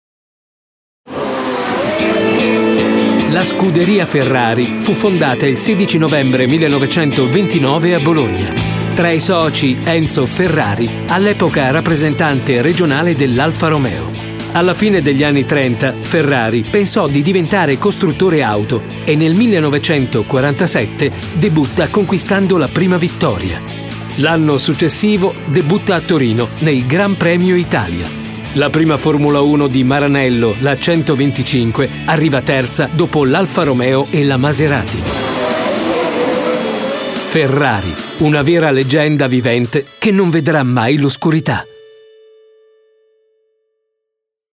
Sprecher italienisch.
Sprechprobe: Industrie (Muttersprache):
Italian voice over artist.